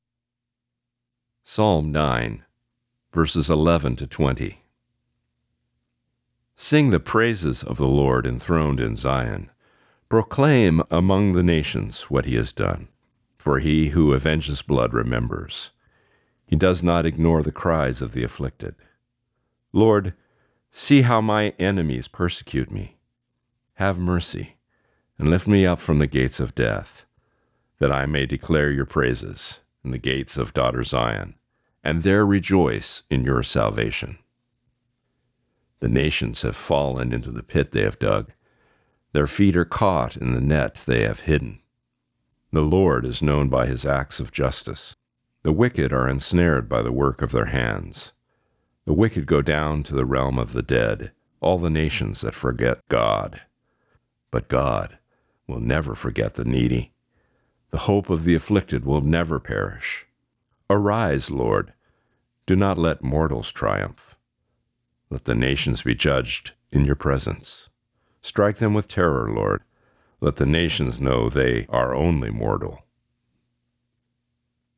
Reading: Psalm 9:11-20